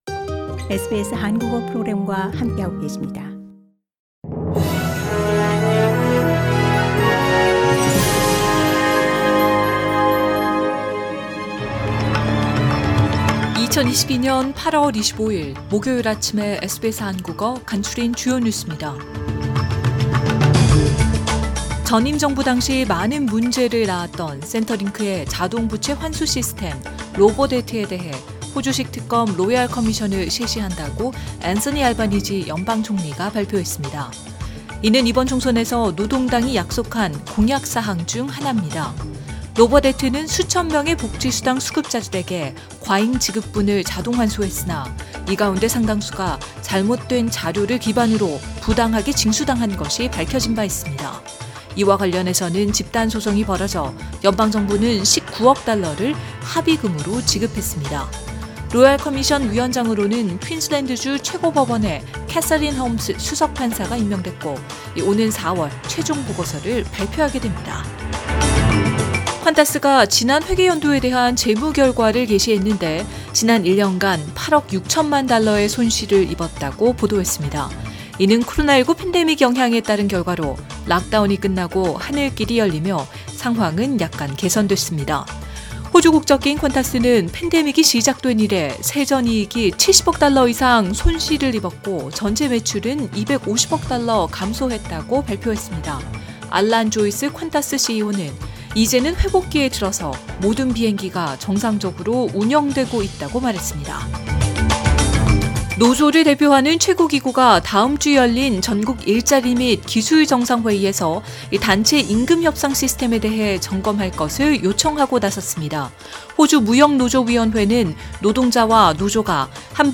2022년 8월 25일 목요일 아침 SBS 한국어 간추린 주요 뉴스입니다.